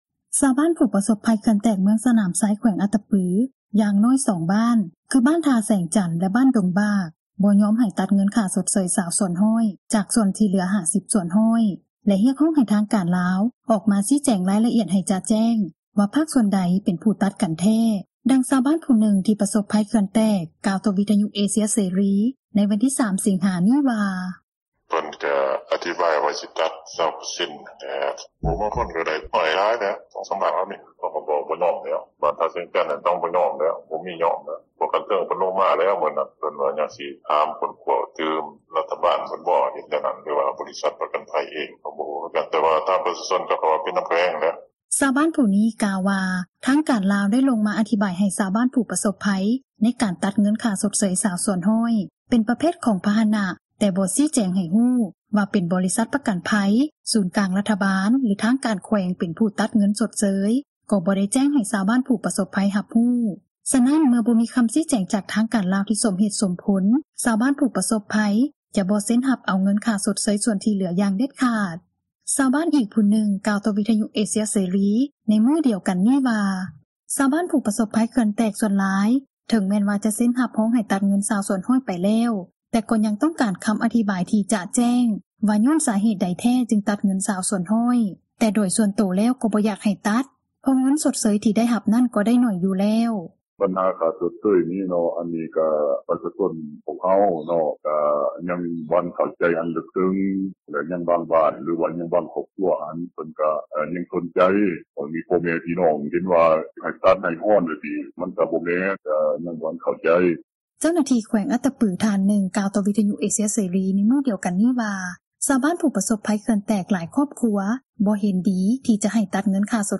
ຊາວບ້ານອີກຜູ້ນຶ່ງ ກ່າວຕໍ່ວິທຍຸເອເຊັຽ ໃນມື້ດຽວກັນນີ້ວ່າ ຊາວບ້ານ ຜູ້ປະສົບພັຍເຂື່ອນແຕກ ສ່ວນຫຼາຍ ເຖິງແມ່ນວ່າ ຈະເຊັນຮັບຮອງ ໃຫ້ຕັດເງິນ 20% ໄປແລ້ວ,  ແຕ່ກໍຍັງຕ້ອງການ ຄໍາອະທິບາຍທີ່ຈະແຈ້ງວ່າ ຍ້ອນສາເຫດໃດແທ້ ຈຶ່ງຕັດເງິນ 20%, ແຕ່ໂດຍສ່ວນໂຕ ກໍບໍ່ຢາກໃຫ້ຕັດ ເພາະເງິນຊົດເຊີຍ ທີ່ໄດ້ຮັບນັ້ນ ກະໄດ້ໜ້ອຍຢູ່ແລ້ວ:
ເຈົ້າໜ້າທີ່ ແຂວງອັດຕະປື ທ່ານນຶ່ງ ກ່າວຕໍ່ວິທຍຸເອເຊັຽເສຣີ ໃນມື້ດຽວກັນນີ້ວ່າ ຊາວບ້ານ ຜູ້ປະສົບພັຍເຂື່ອນແຕກ ຫຼາຍຄອບຄົວ ບໍ່ເຫັນດີ ທີ່ຈະໃຫ້ຕັດ ເງິນຊົດເຊີຍ 20% ເຊິ່ງທາງການແຂວງ ກໍໄດ້ລົງເກັບກໍາ ຂໍ້ມູນເພີ່ມຕື່ມ ແລະ ທໍາຄວາມເຂົ້າໃຈ ໃຫ້ຊາວບ້ານຢ່າງຣະອຽດ:
ກ່ຽວກັບບັນຫານີ້ ທ່ານອຸ່ນຫຼ້າ ໄຊຍະສິດ, ຄະນະປະຈໍາພັກແຂວງ, ຮອງເຈົ້າແຂວງ ແຂວງອັດຕະປື ໄດ້ຊີ້ແຈງ ຕໍ່ວິທຍຸເອເຊັຽເສຣີ ໃນມື້ດຽວກັນນີ້ວ່າ ການຕັດເງິນ 20% ນັ້ນ, ບໍ່ກ່ຽວກັບ ເງິນຊົດເຊີຍ 50% ແຕ່ເປັນການຕັດມູນຄ່າການຊົມໃຊ້ ປະເພດເຄື່ອງໃຊ້ຄົວເຮືອນ ແລະ ພາຫະນະ ເຊິິ່ງກ່ອນໜ້ານີ້ ໄດ້ຕີລາຄາ ໃນມູລຄ່າ 100% ເຕັມ, ແຕ່ທາງບໍຣິສັດ ຜູ້ປະເມີນ ຄ່າຊົດເຊີຍ ຈາກປະເທດໄທຍ ເຫັນວ່າ ບໍ່ສາມາດຈ່າຍ ເງິນຊົດເຊີຍ ໃນລາຄາເຕັມ 100% ໄດ້.